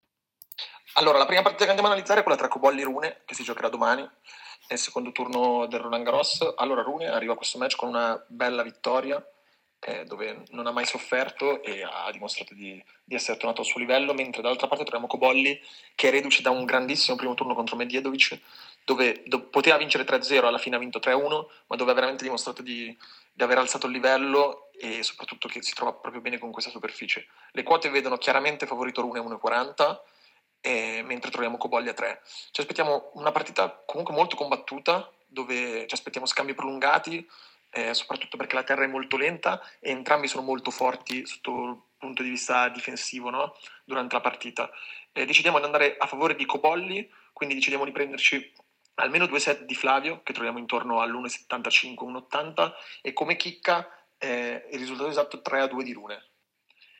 Ecco l’audio analisi con i consigli per le scommesse sul Tennis